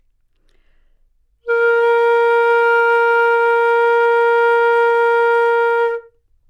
长笛单音 " 单音的整体质量 长笛 A4
描述：在巴塞罗那Universitat Pompeu Fabra音乐技术集团的goodsounds.org项目的背景下录制。单音乐器声音的Goodsound数据集。 instrument :: flutenote :: Asharpoctave :: 4midi note :: 58microphone :: neumann U87tuning reference :: 442goodsoundsid :: 22dynamic_level :: mf